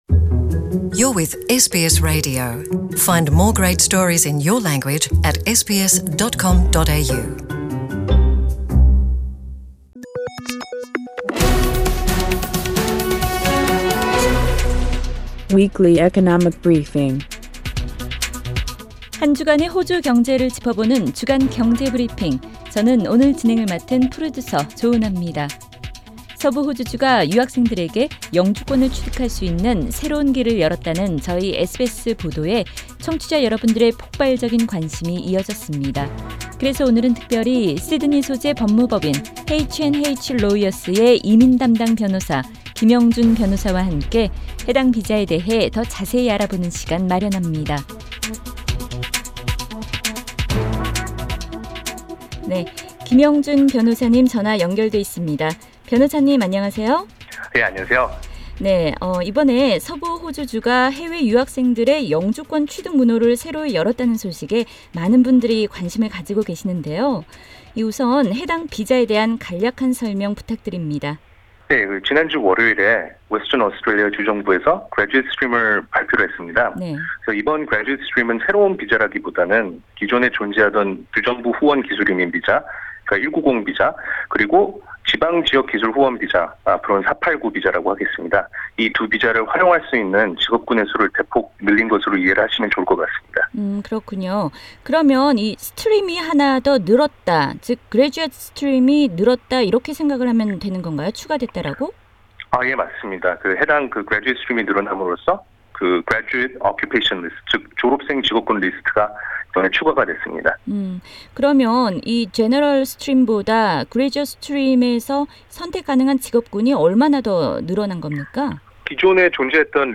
전체 인터뷰 내용은 상단의 팟 캐스트를 통해 들으실 수 있습니다.